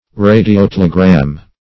Search Result for " radiotelegram" : The Collaborative International Dictionary of English v.0.48: Radiotelegram \Ra`di*o*tel"e*gram\ (r[=a]`d[i^]*[o^]*t[e^]l"[-e]*gr[a^]m), n. A message transmitted by radiotelegraph.
radiotelegram.mp3